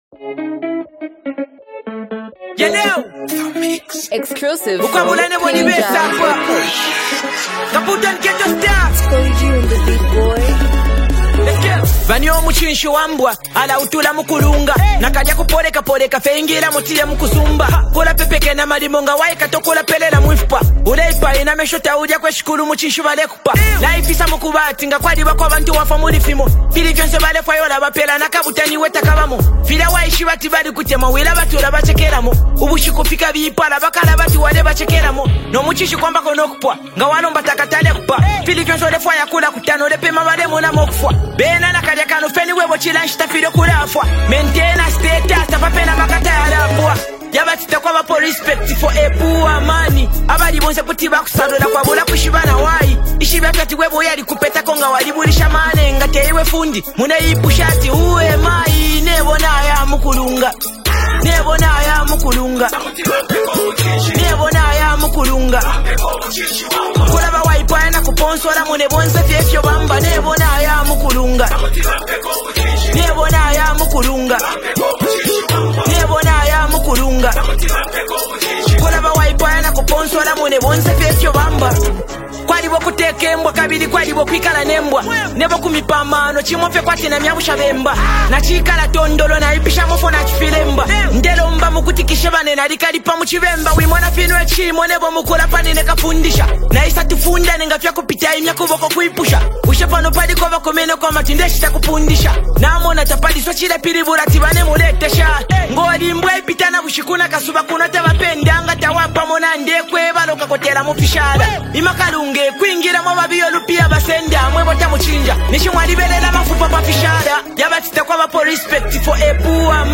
rhythmic flow that sits comfortably on the beat